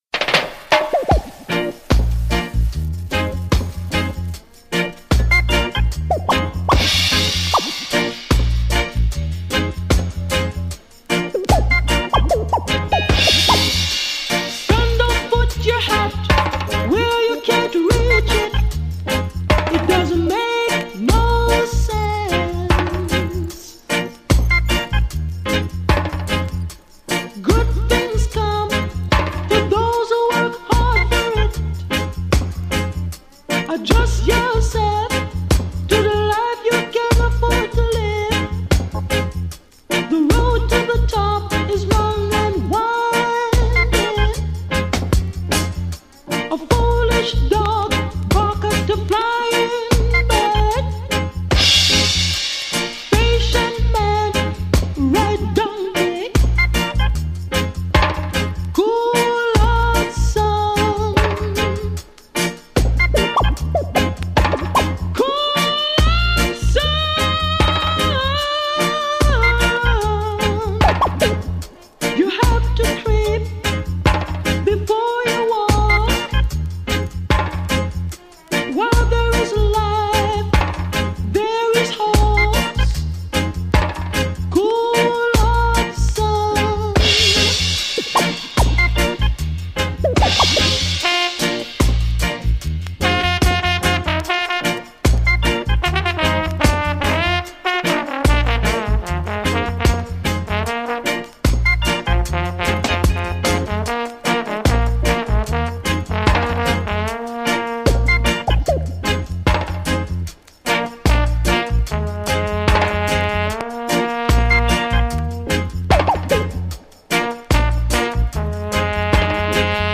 RIDDIM!